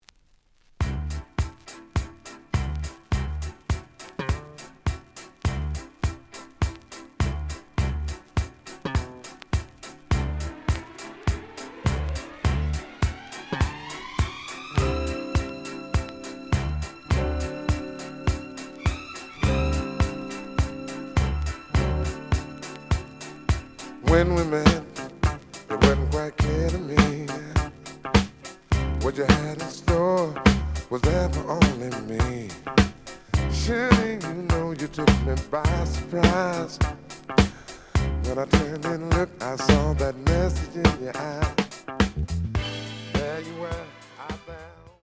The audio sample is recorded from the actual item.
●Genre: Disco
Looks good, but slight noise on beginning of both sides.